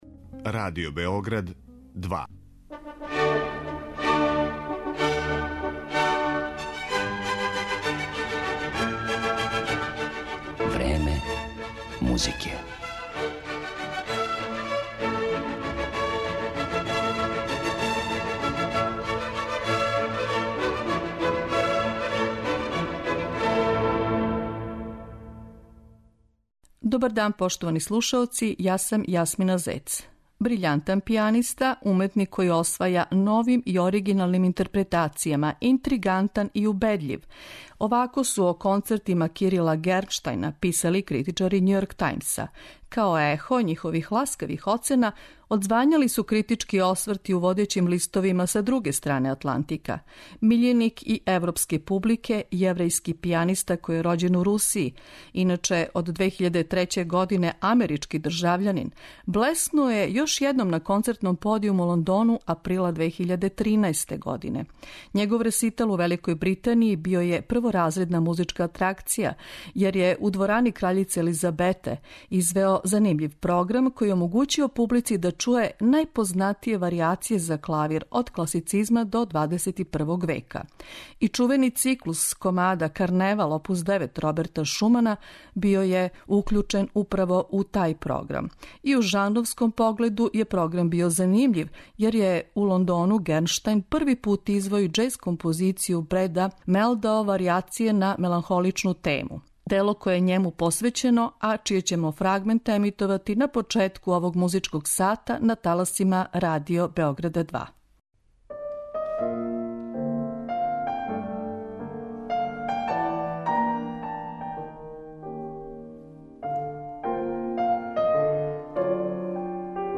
Емисију 'Време музике' посвећујемо славном пијанисти Кирилу Гернштајну.
До 14 часова и 55 минута, слушаоци ће моћи да чују снимке остварене на концерту у Лондону, априла 2013. године. Емитоваћемо композиције Јоханеса Брамса, Сергеја Рахмањинова, Јозефа Хајдна и Роберта Шумана.